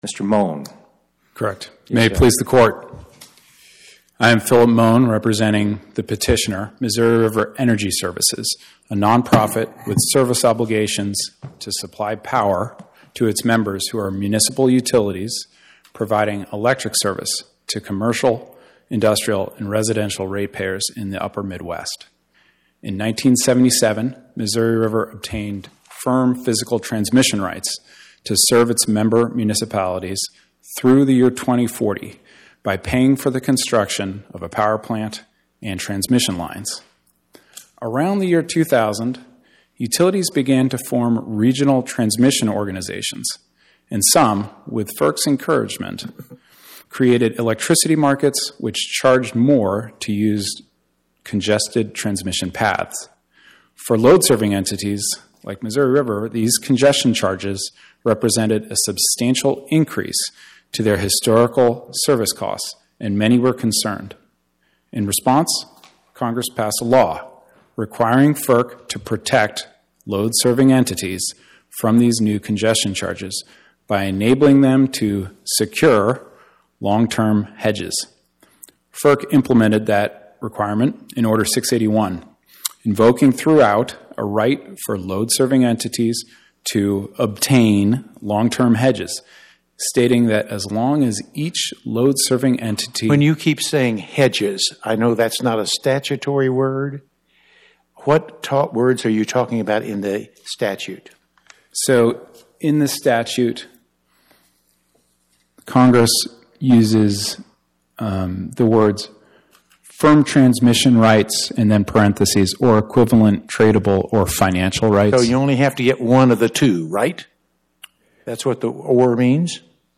My Sentiment & Notes 24-3161: Missouri River Energy Services vs FERC Podcast: Oral Arguments from the Eighth Circuit U.S. Court of Appeals Published On: Wed Oct 22 2025 Description: Oral argument argued before the Eighth Circuit U.S. Court of Appeals on or about 10/22/2025